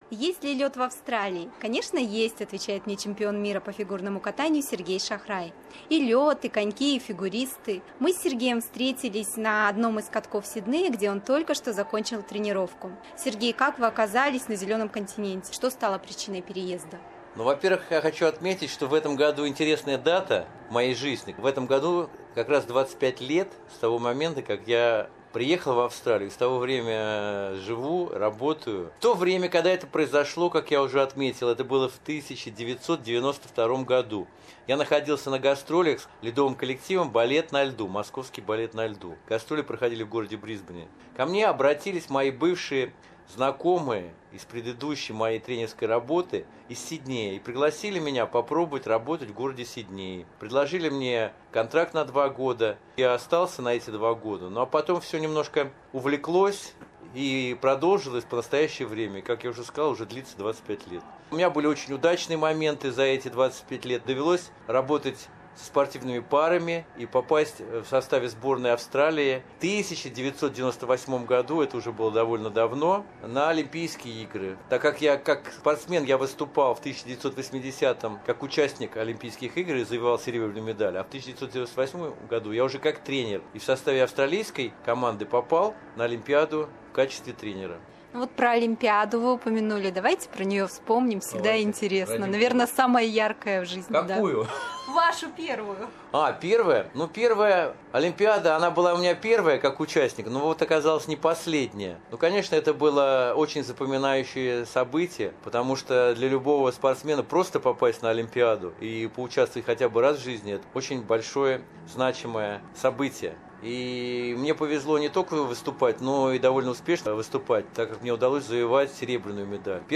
The silver medalist of the 1980 Olympic Games, world and European champion Sergei Shakhrai has been living in Australia for a quarter of a century. During the interview, we talked about his coaching, a new sporting hobby and a recent meeting with his star partner Marina Cherkasova.